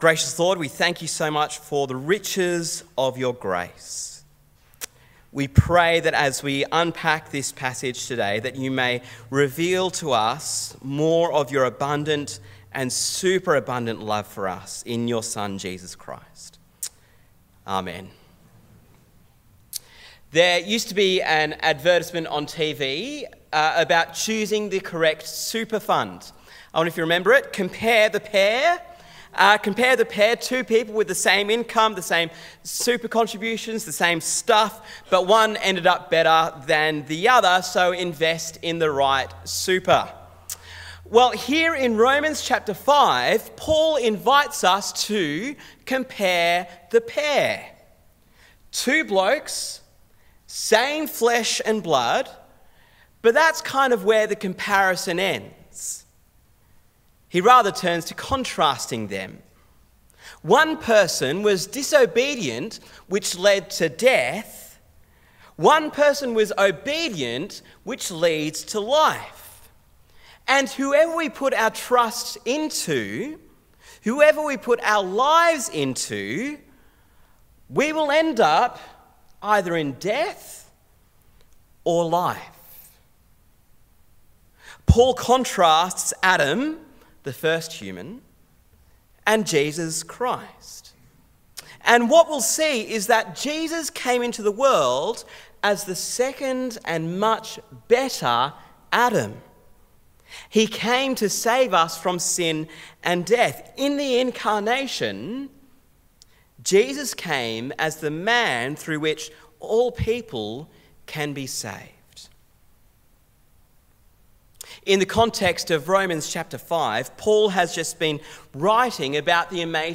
Sermon on Romans 5:12–21 - Death in Adam, Life in Christ